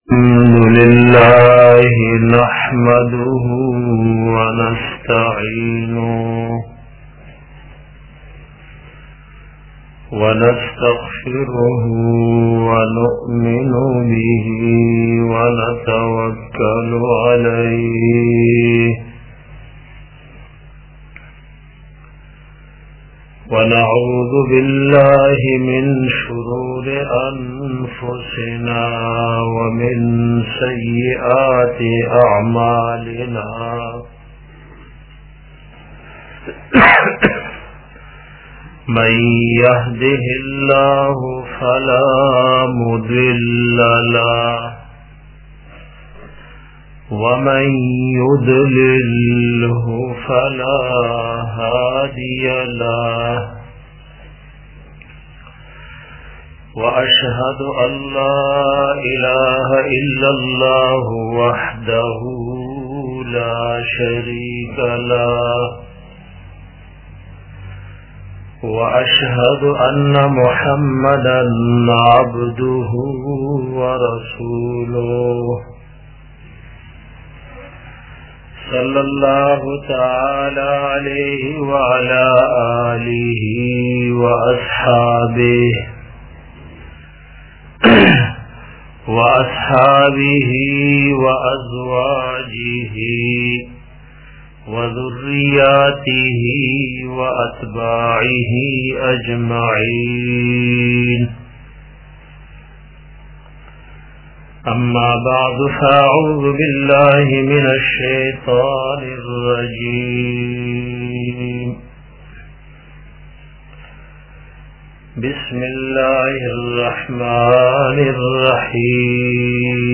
bayan da rooz e qiyamat
bayan-da-rooz-e-qiyamat-1.mp3